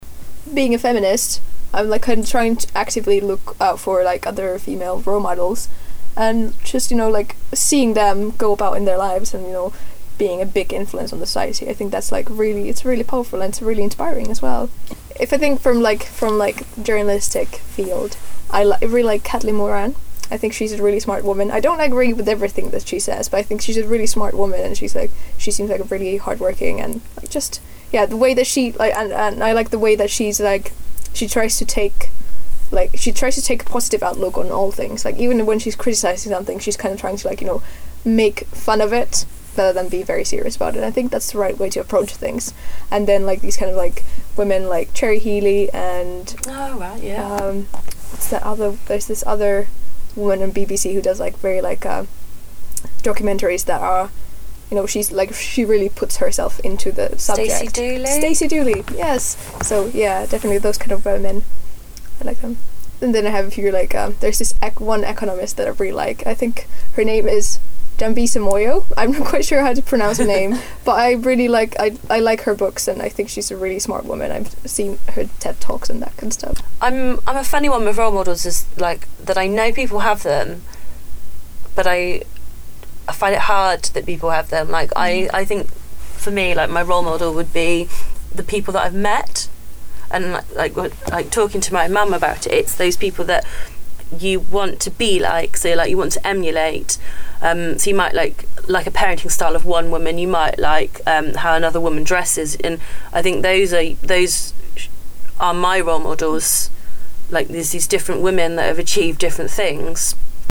Conversations: Who Are Your Role Models?